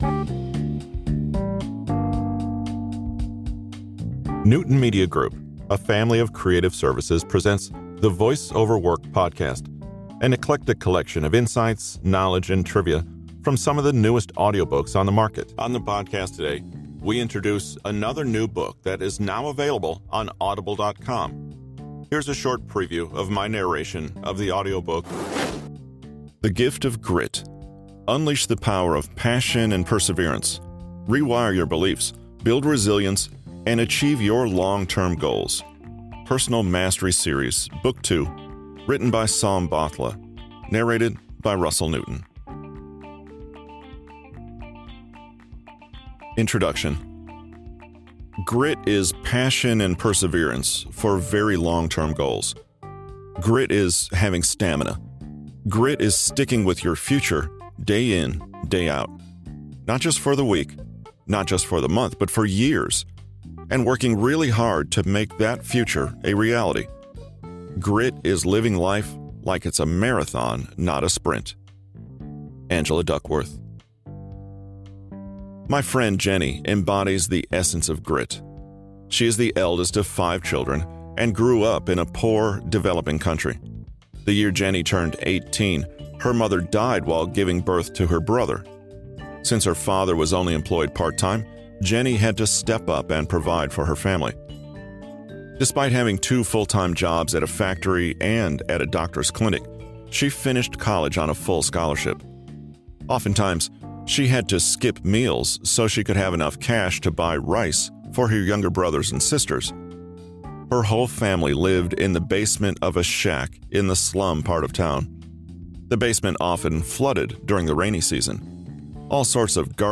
Quickly and easily listen to Voice over Work - An Audiobook Sampler for free!